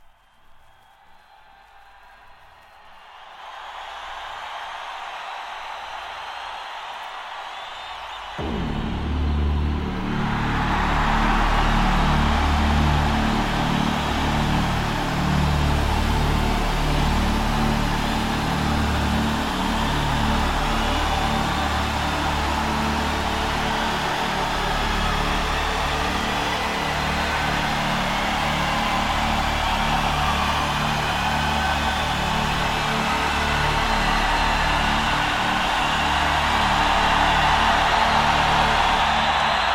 Hard Rock, Heavy Metal, Live Album